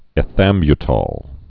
(ĕ-thămbyə-tôl, -tōl, -tŏl)